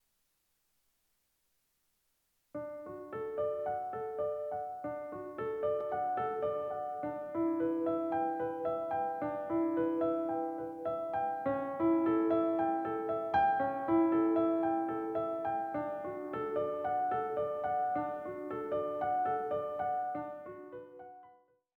Now listen to this one, transposed into the key of D:
That was better, but still sounded different than the first, especially this interval: